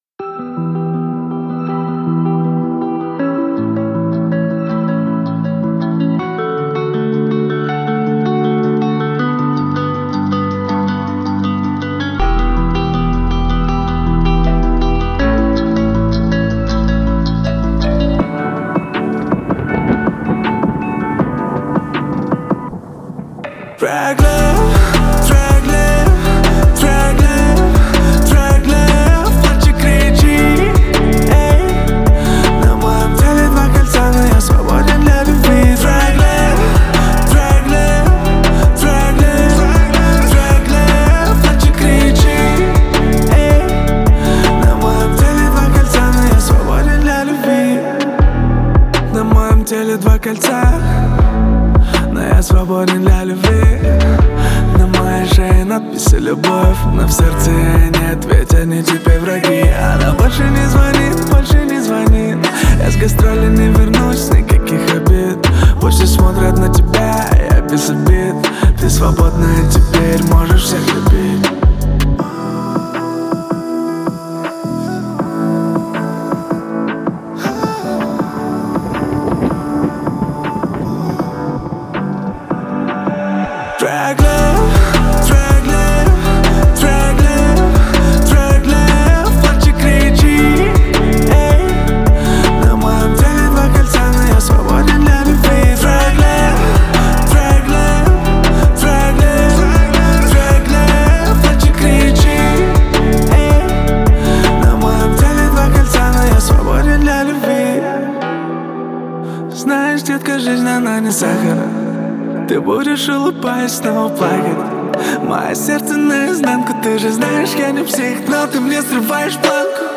это трек в жанре поп-рэп